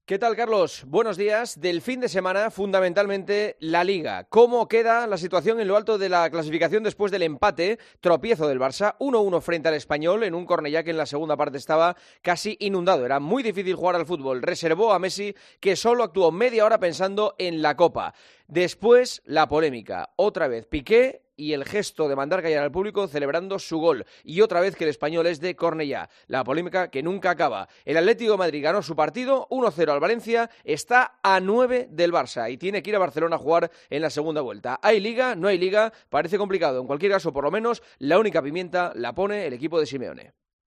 Escucha el comentario del director de 'El Partidazo de COPE', Juanma Castaño, en 'Herrera en COPE'